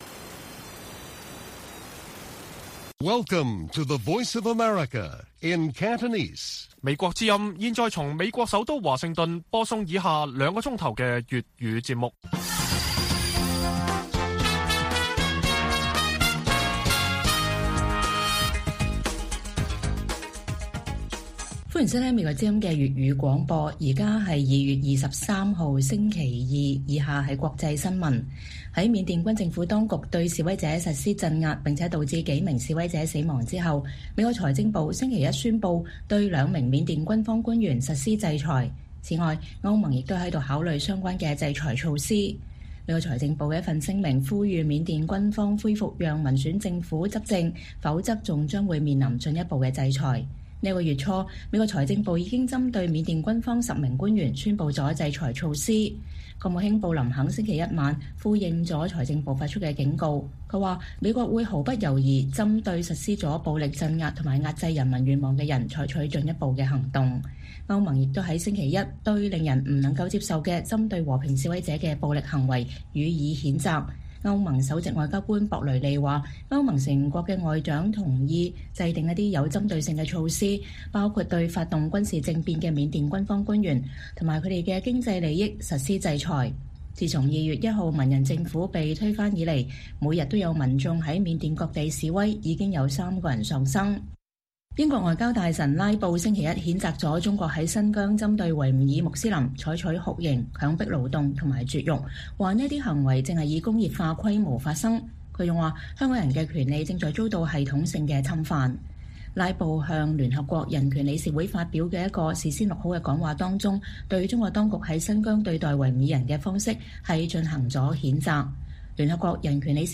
粵語新聞 晚上9-10點